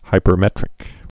(hīpər-mĕtrĭk)